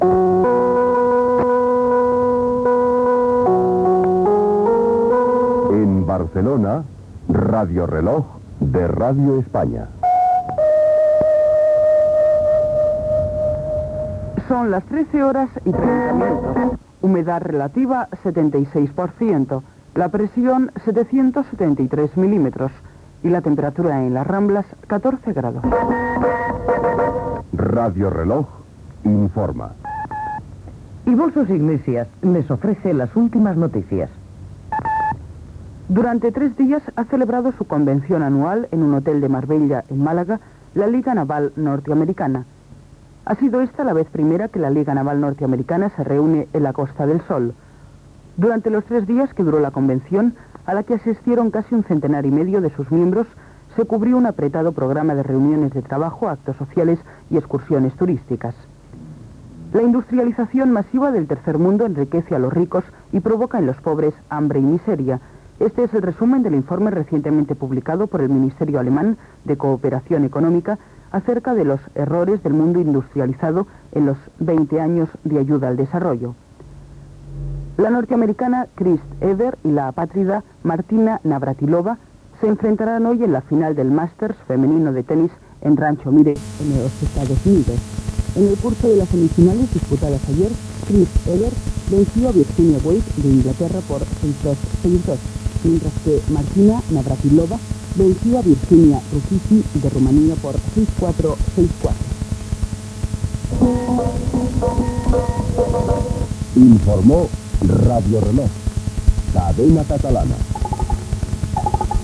Indicatiu, hora, estat del temps i informatiu.
Informatiu